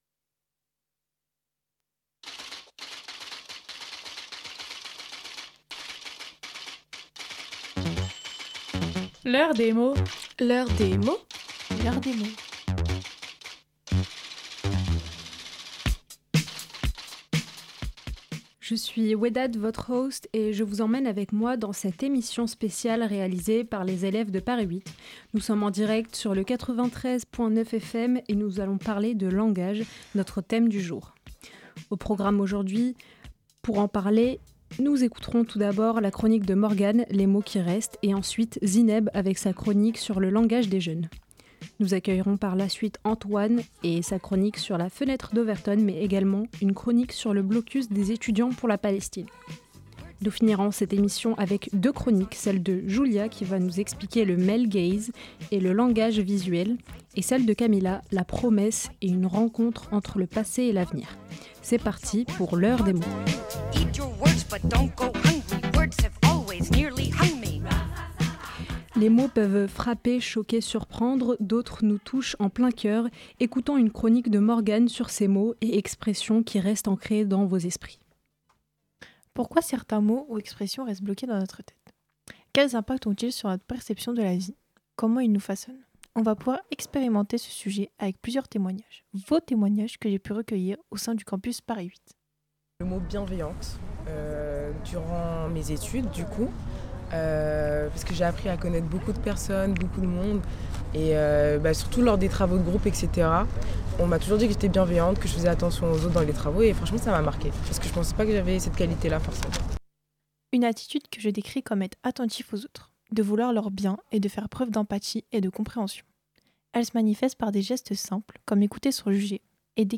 Les étudiants et étudiantes de l'Université Paris 8 ont préparé, écrit et enregistré leur émission sur la thématique du langage : "L'heure des mots".